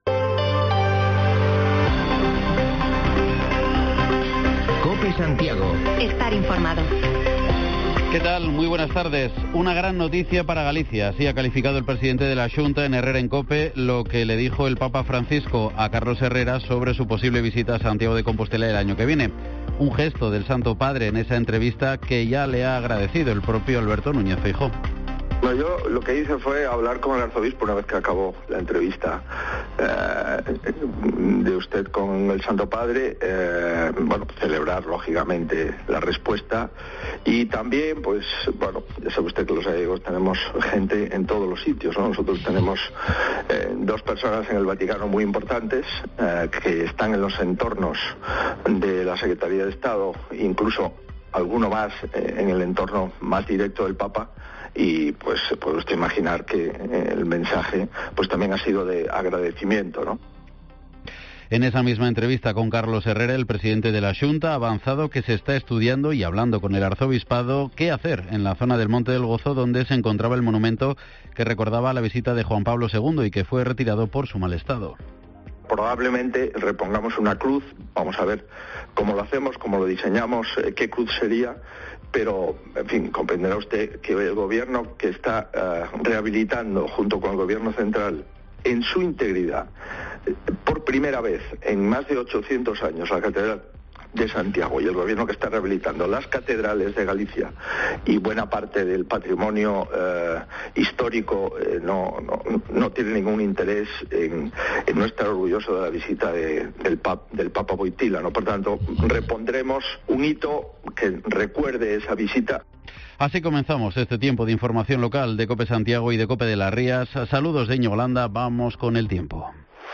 Podcast: Informativo local Mediodía en Cope Santiago y de las Rías 03/09/2021